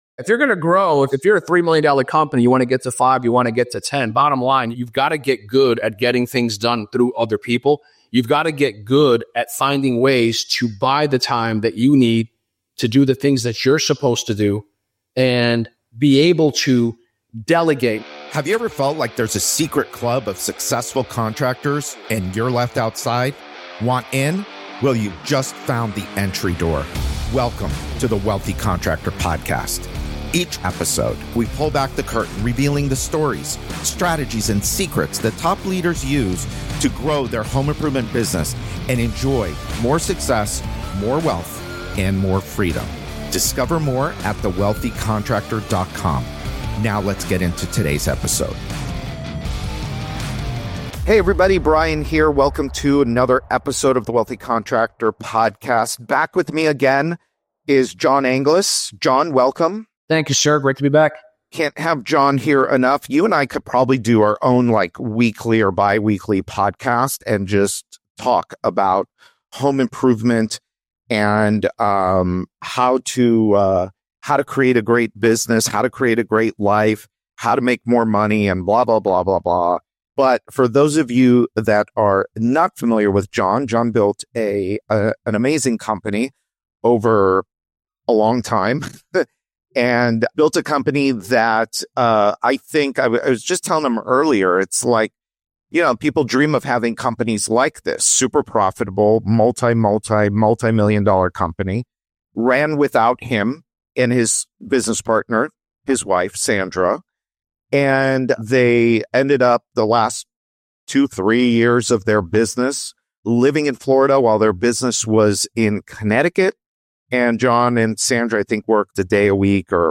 The Wealthy Contractor Podcast shares interviews with home improvement industry insiders, giving you the tools and insights to achieve greater success, wealth, and freedom.